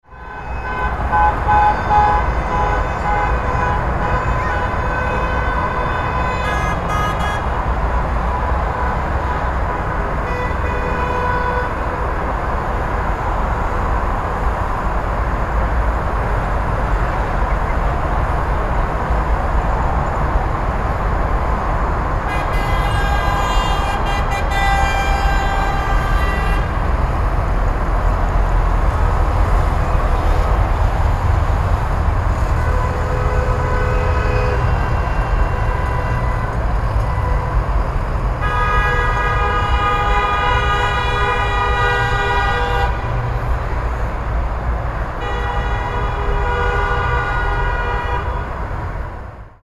Cars Passing Through Traffic Jam Honking Sound Effect
Realistic sounds of cars honking nervously in a traffic jam. Perfect urban city street ambience with automobile horns, congestion, and background noise.
Cars-passing-through-traffic-jam-honking-sound-effect.mp3